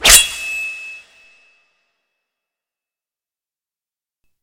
Катана меч самурая